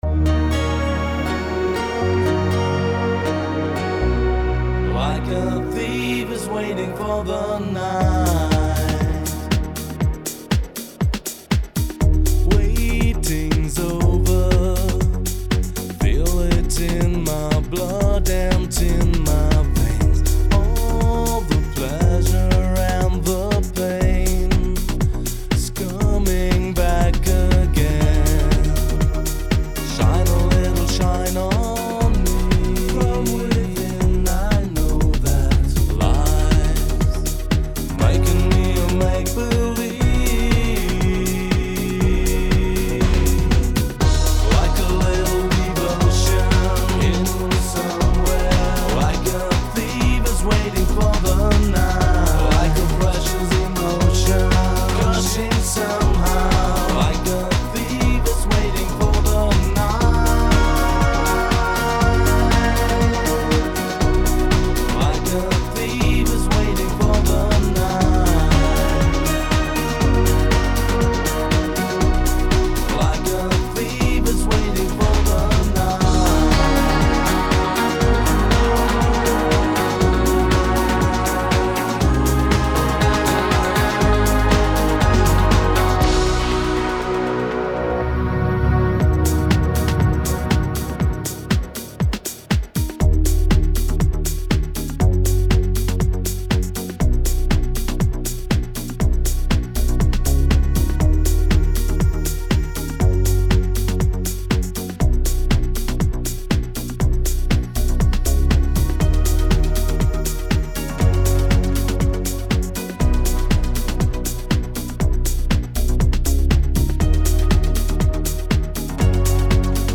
Extended Version
speziell für Clubs optimierte Version